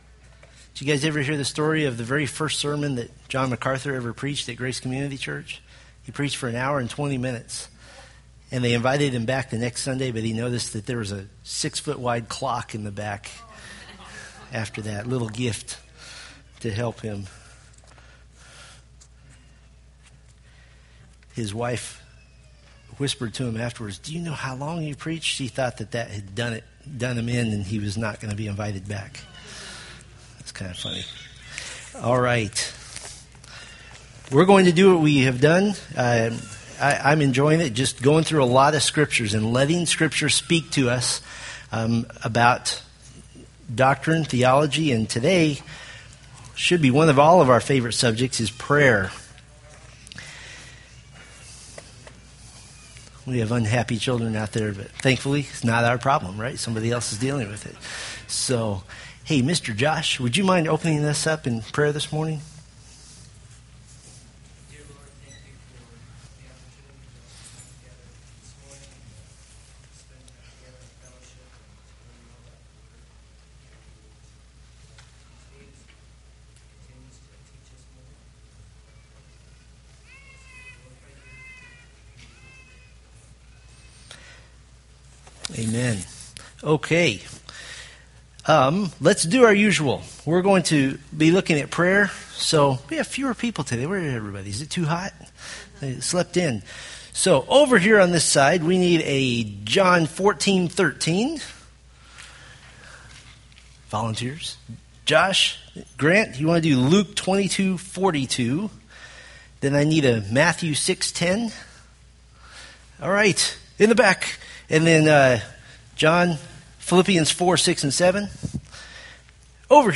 Date: Aug 11, 2013 Series: Fundamentals of the Faith Grouping: Sunday School (Adult) More: Download MP3